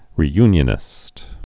(rē-ynyə-nĭst)